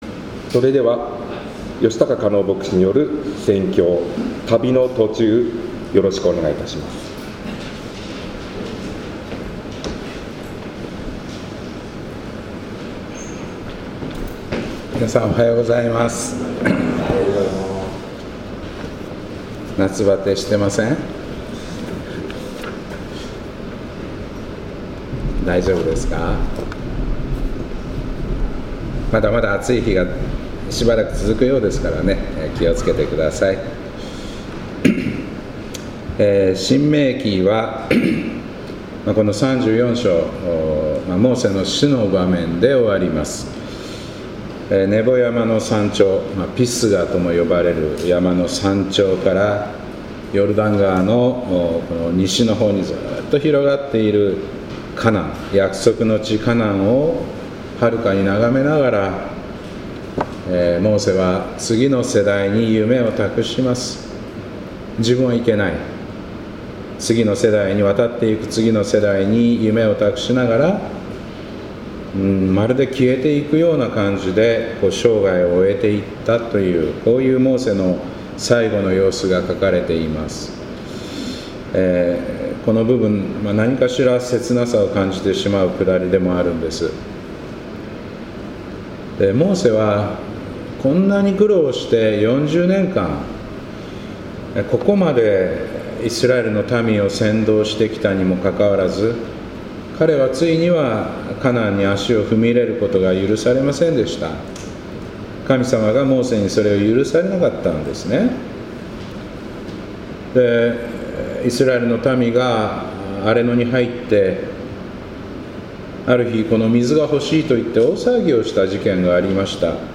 2025年8月31日礼拝「旅の途中」